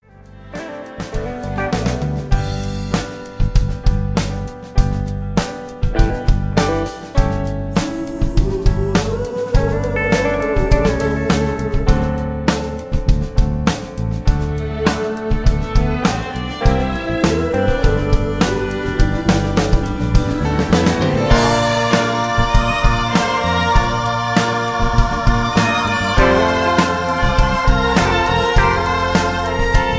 backing tracks
karaoke
country music